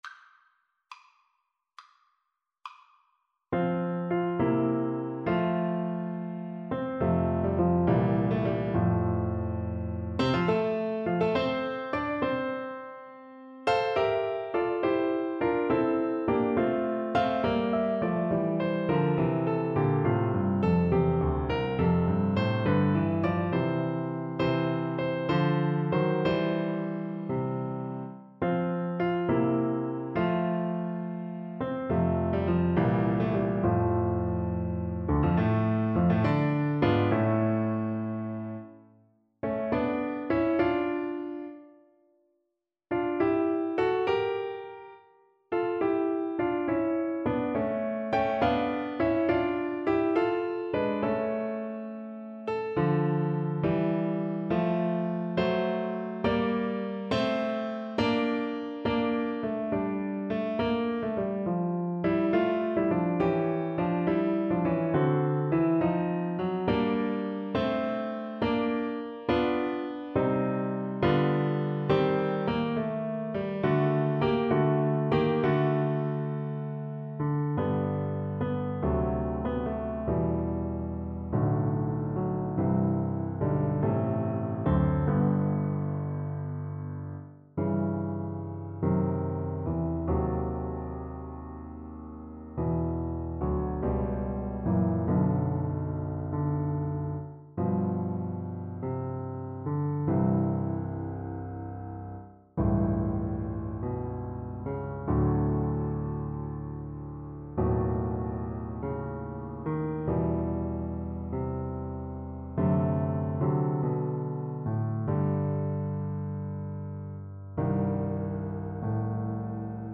12/8 (View more 12/8 Music)
Classical (View more Classical Flute Music)